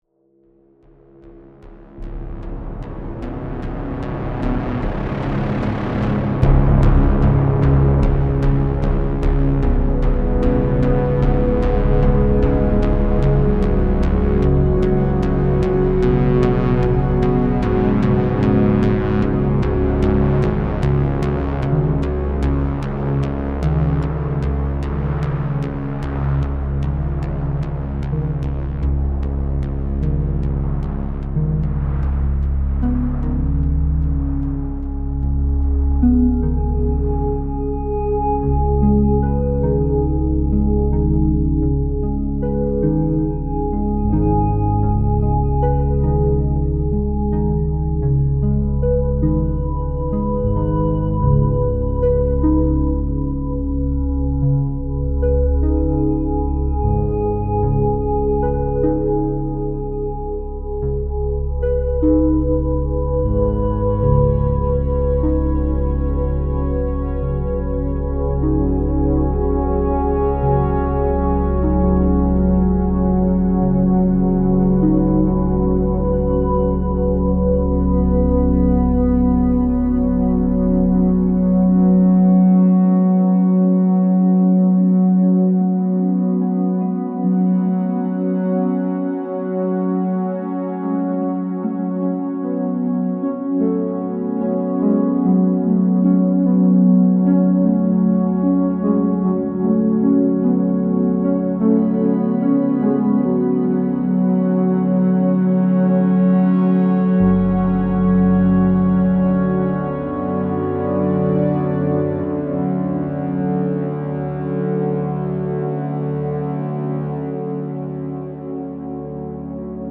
Cinematic Ambient Diva Presets
Immerse yourself in a world of rich, organic sound—crafted to inspire composers, producers, and sound designers. This collection harnesses the warmth of analog synthesis, delivering expansive, evolving textures, deep basses, ethereal pads, and evocative sequences.